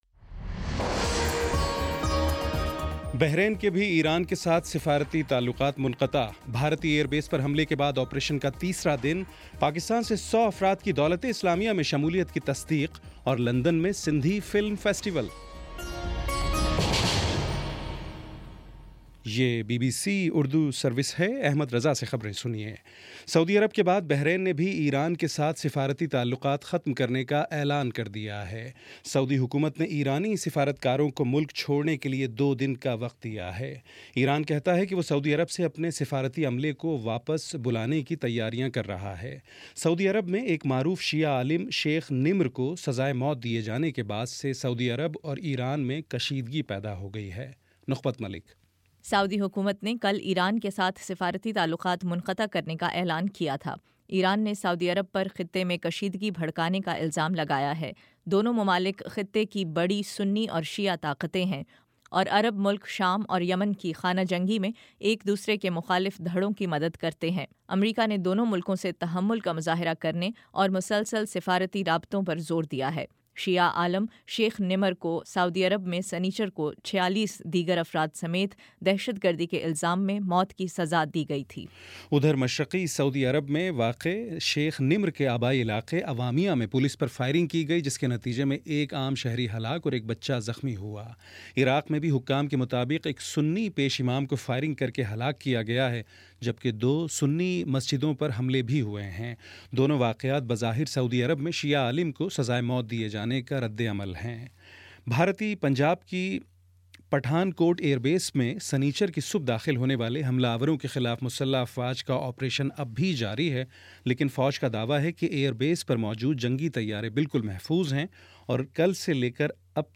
جنوری 04 : شام پانچ بجے کا نیوز بُلیٹن